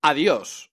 voz nș 0151